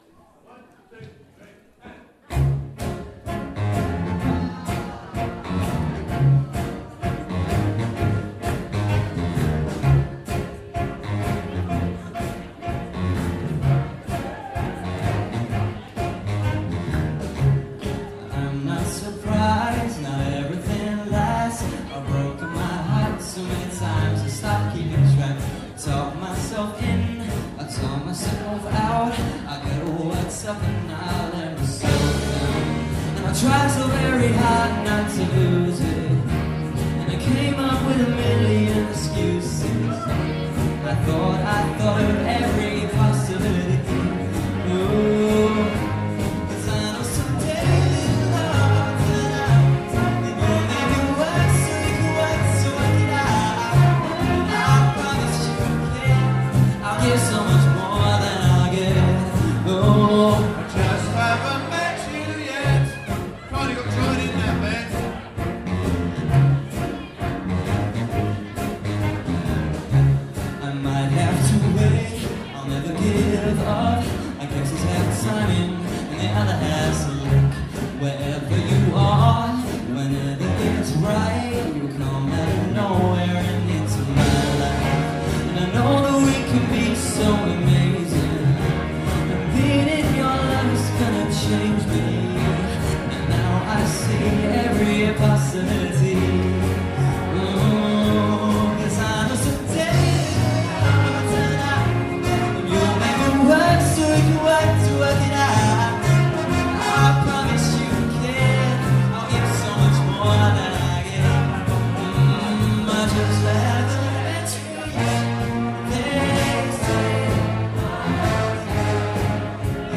From the Big Band Evening March 2017